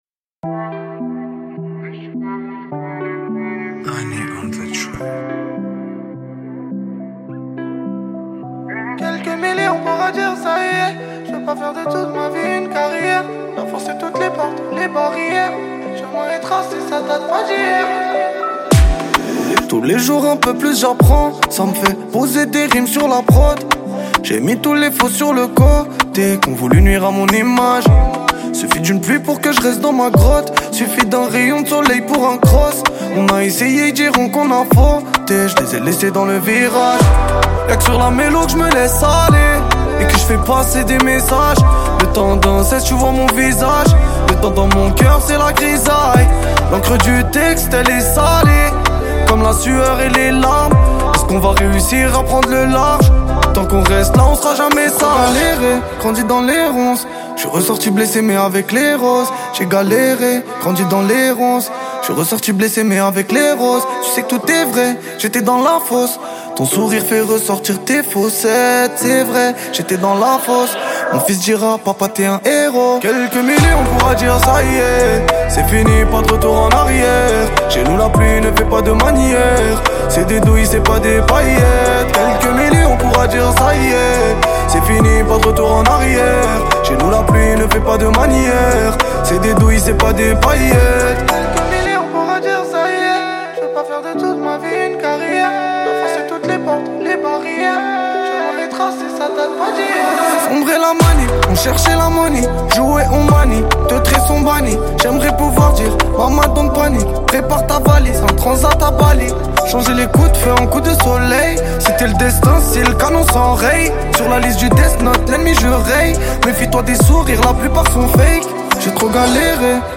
Genres : french rap, french r&b, pop urbaine